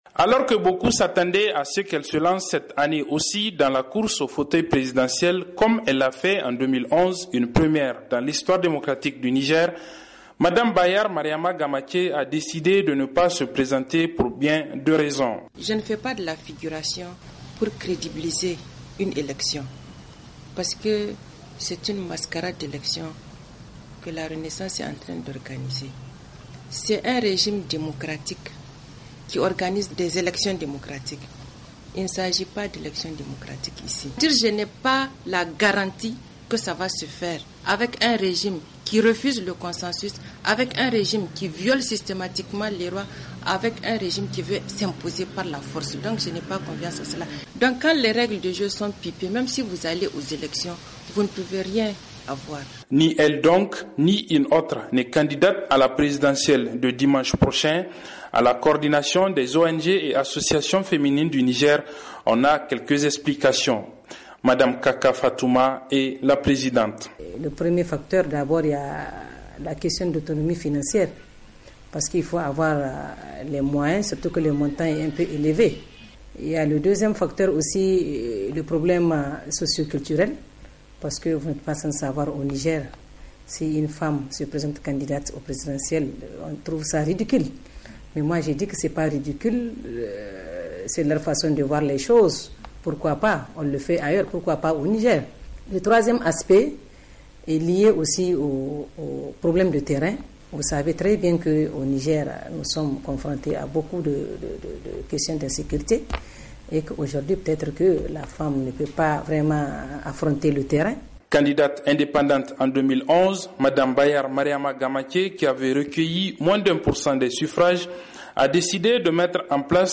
Le reportage à Niamey